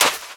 High Quality Footsteps
STEPS Sand, Run 10.wav